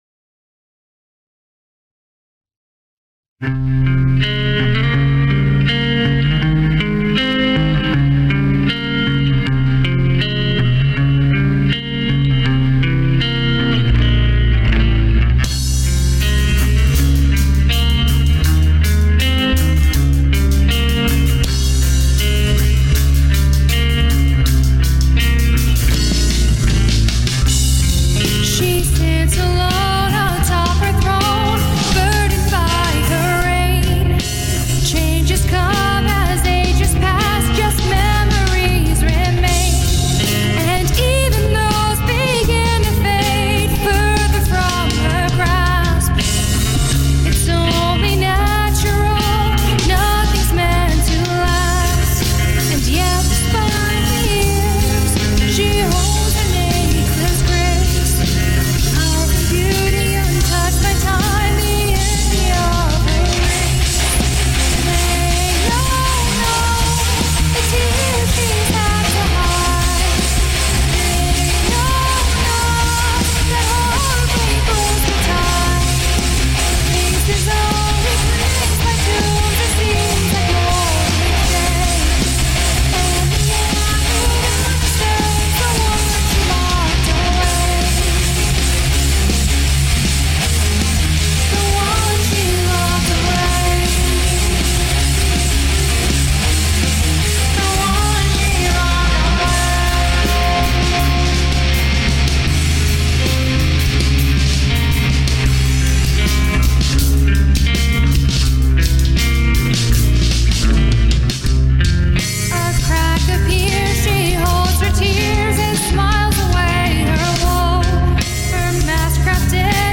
Vocals
Drums
Guitars, Bass